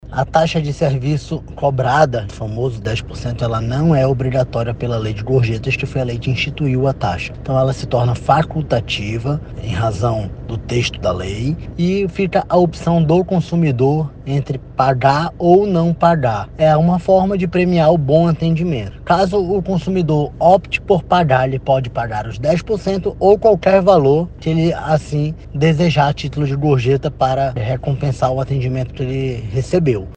No entanto, é importante destacar que essa taxa é opcional e os consumidores não são legalmente obrigados a pagá-la, como explica o diretor-presidente do Procon, Jalil Fraxe.
SONORA-1-JALIL-FRAXE.mp3